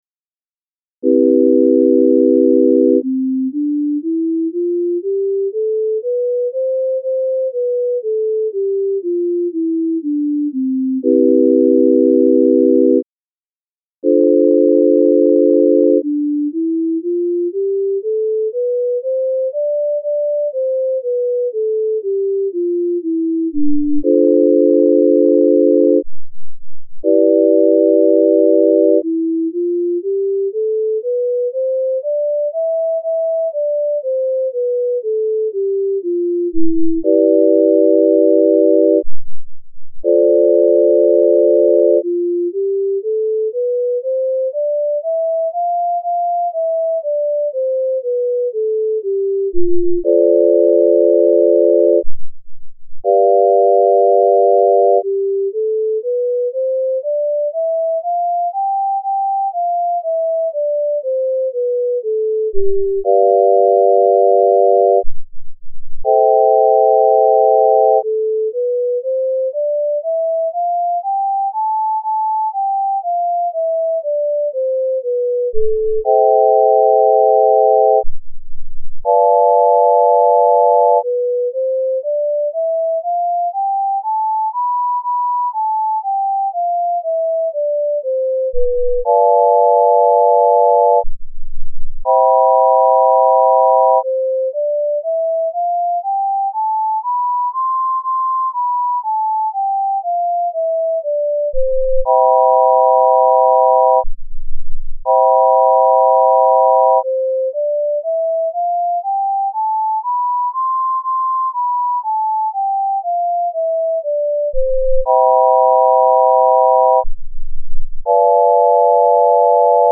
C Major Scale Using the Just Scale